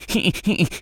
rabbit_squeak_angry_03.wav